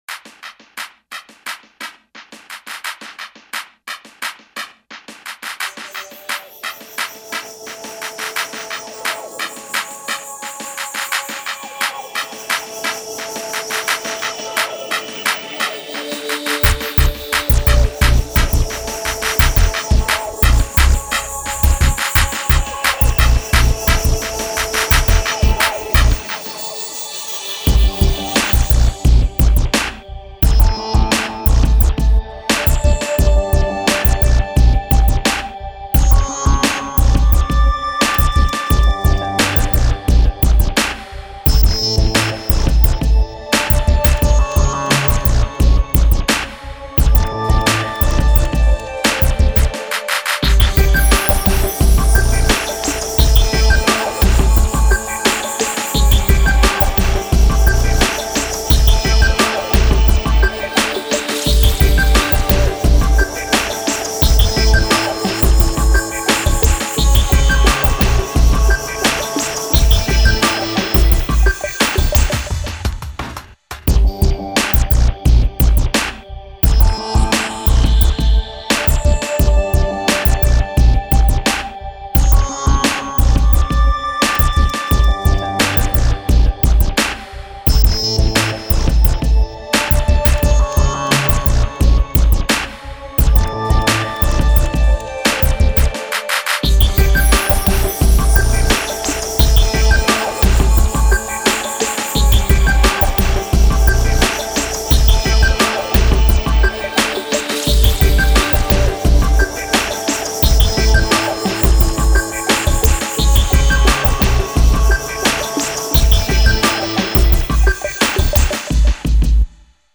FantasyLife_TheSpace_instrumentalmix_MASTEREDMP3.mp3